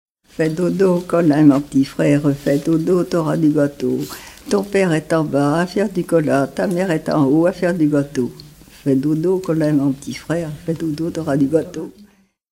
berceuse